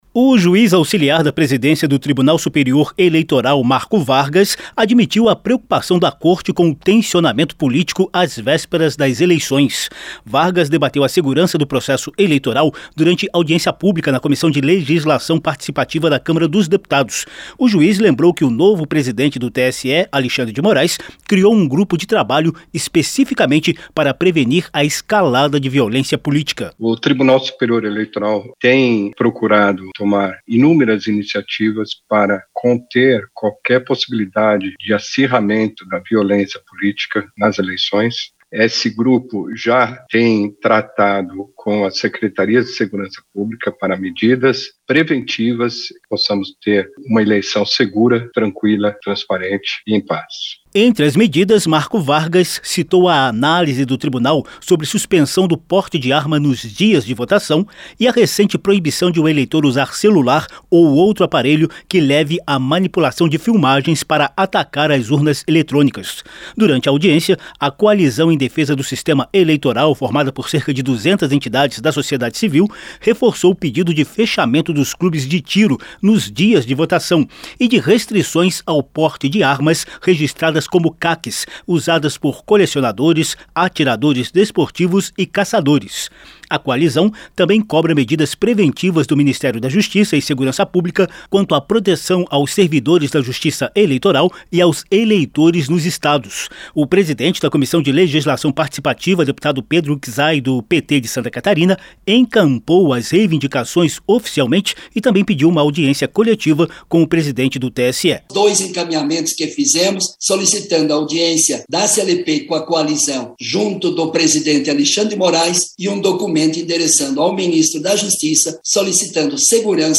Reportagem